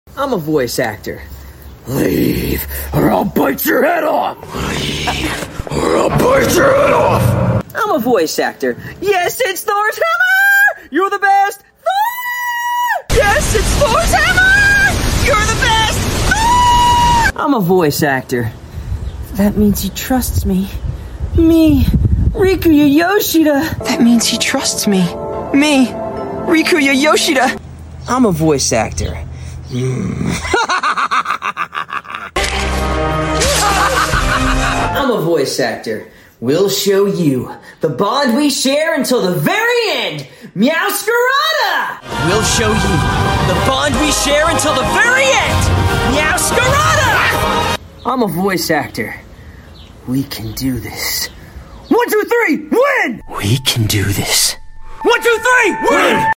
I’m Also An ANIME VOICE Sound Effects Free Download